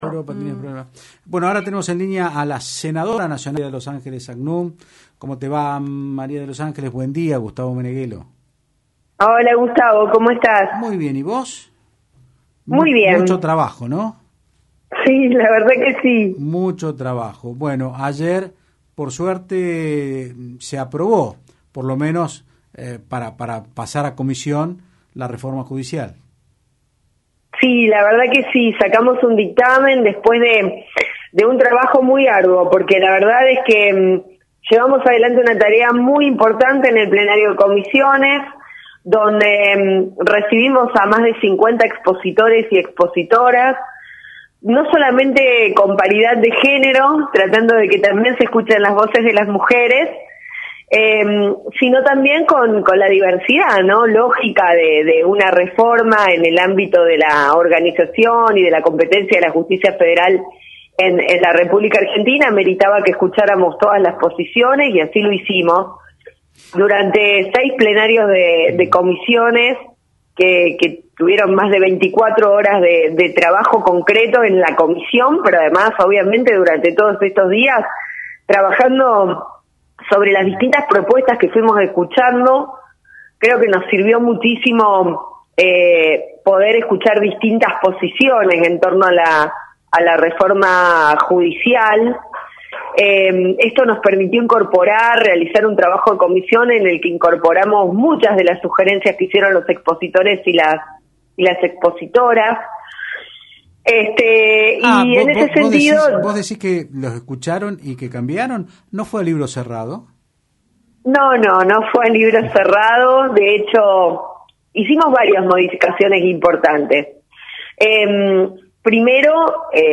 La Senadora Nacional María de los Ángeles Sacnun aseguró en Otros Ámbitos (Del Plata Rosario 93.5) que como lo planteó el Presidente de la Nación el proyecto de Reforma Judicial se trata de un proyecto que no llegó cerrado al Senado sino que llegó para que podamos discutirlo e introducirle aquellas reformas que consideramos podían enriquecerlo para fortalecer a la Justicia Federal.